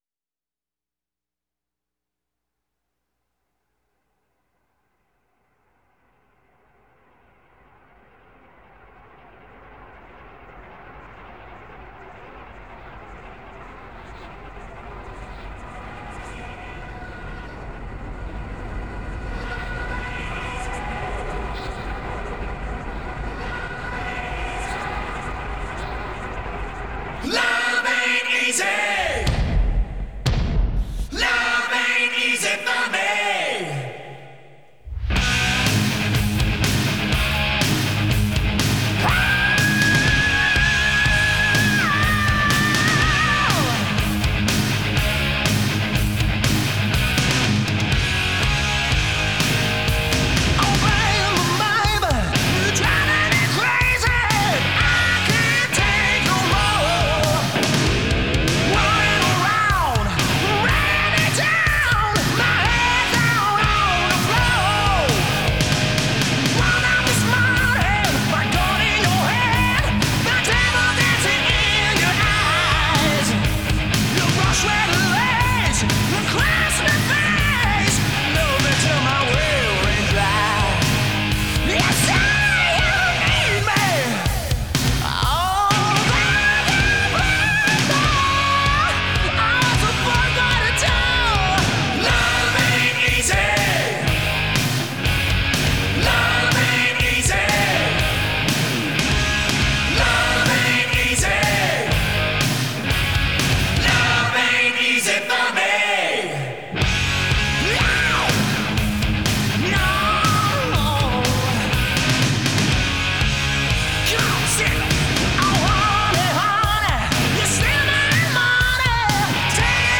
американская глэм-метал-группа